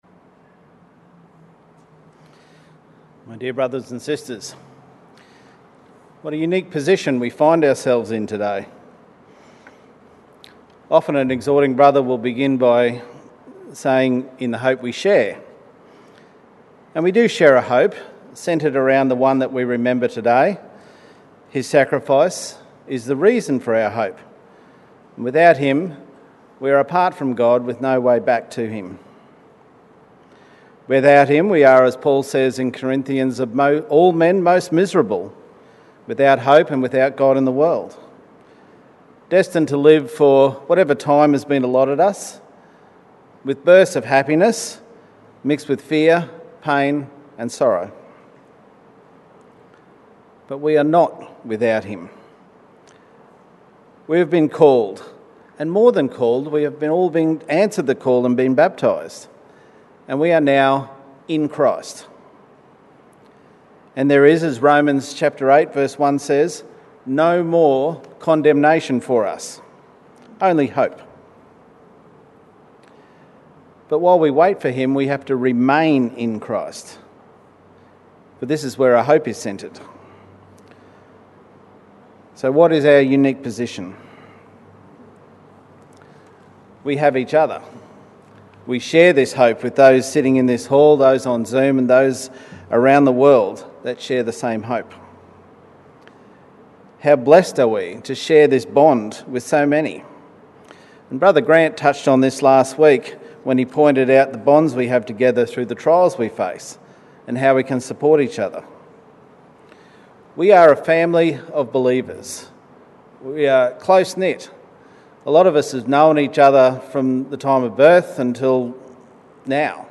Exhortation - Family in the Bonds of the Truth - Know Your Bible